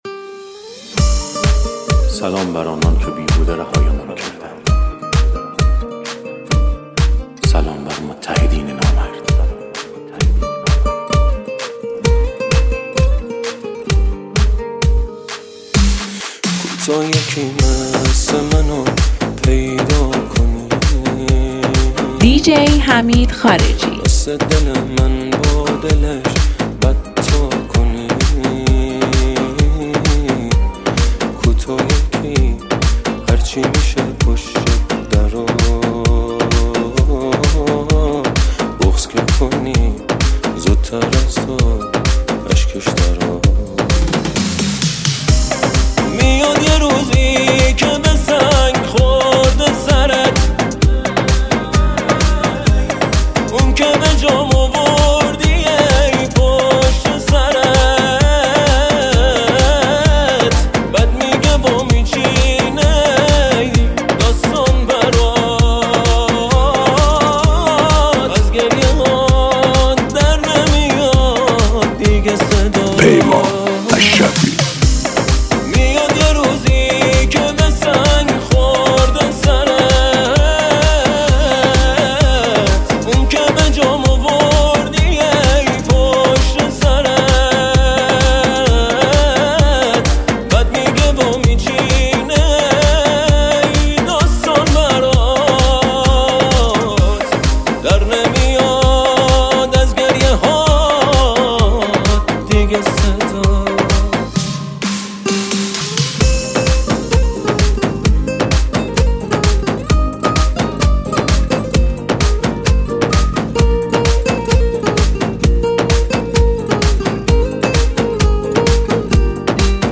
این میکس هاوس جدید و فوق‌العاده رو به هیچ وجه از دست ندید!
با سبکی هاوس و پرانرژی